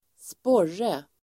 Uttal: [²sp'år:e]